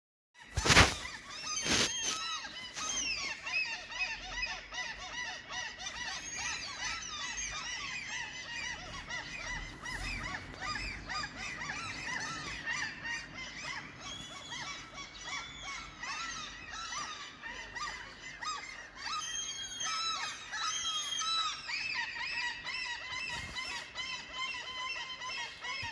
De har fört oväsen under dygnets alla 24 timmar, de skitar ner bilar, fönster, trädgårdsmöbler, barn, människor, trottoarer osv.
I fredags eftermiddag satt jag i trädgården och fick för mig att spela in måsarnas ljud –
De är i full extas och bara vrålar.
fiskmas.m4a